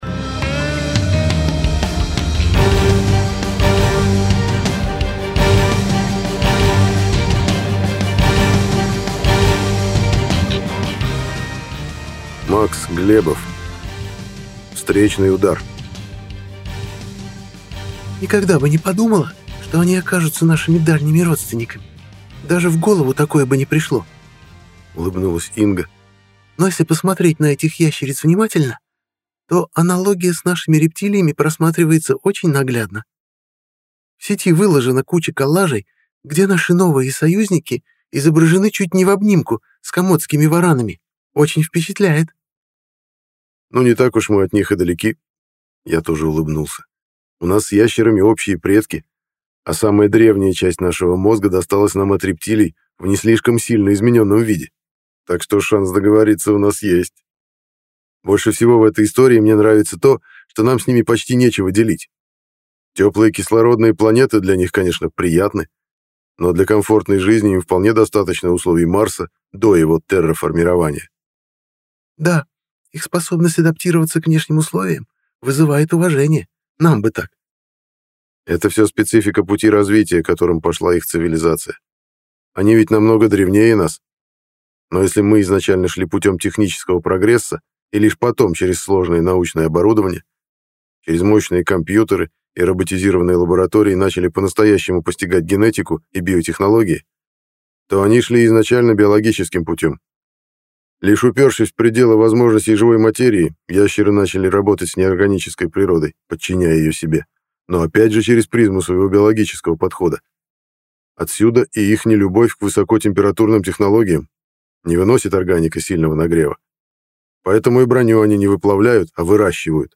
Аудиокнига Встречный удар | Библиотека аудиокниг